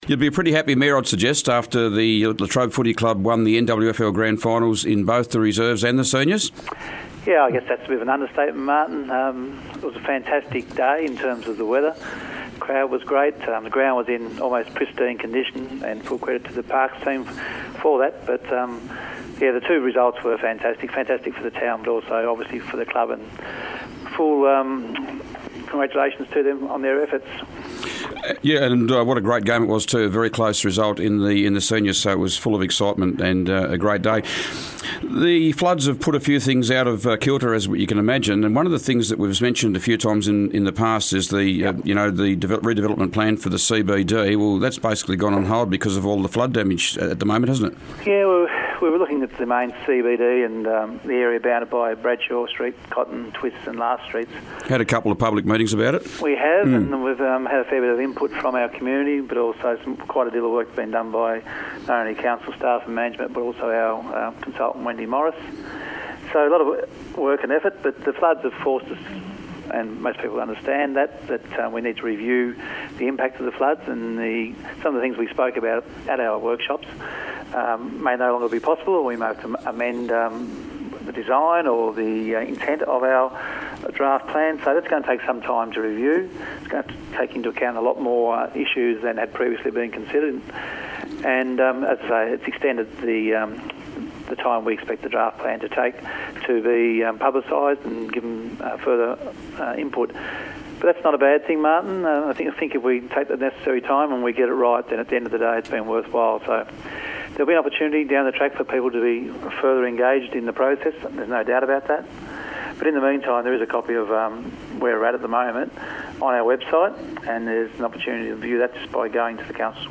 Latrobe Mayor Peter Freshney was today's Mayor on the Air. Peter spoke about recent premiership success for the Latrobe Football Club, the Latrobe CD redevelopment, the NBN (National Broadband Network), school holiday activities, upcoming activities at the Port Sorell Bowls Club, the Sassafras Community Hall, tenders to operate the Australian Axemen's Hall of Fame at Bells Parade, and etiquette when using shared walking and bicycle pathways . . .